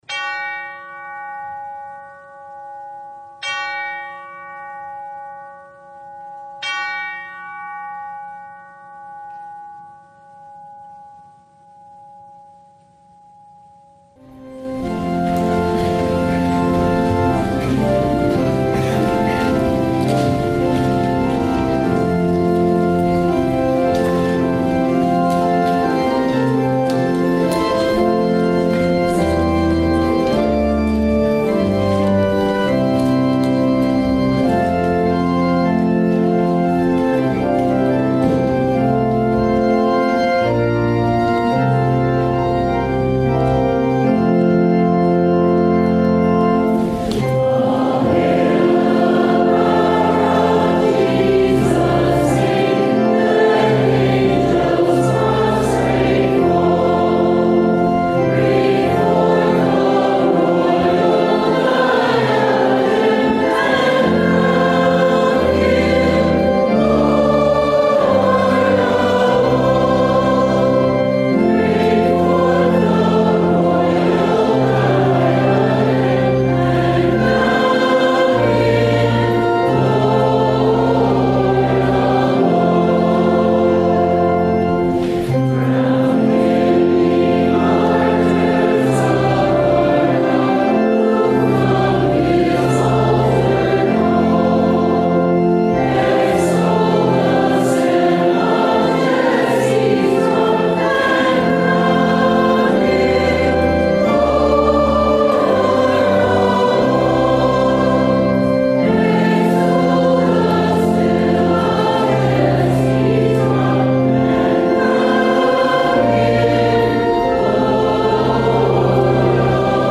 Service Type: The Feast of the Ascension of Our Lord